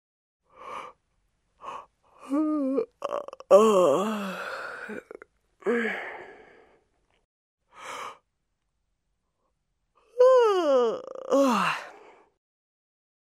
На этой странице собраны натуральные звуки зевания и потягиваний — от утренних до вечерних.
Женщина зевнула и сладко потянулась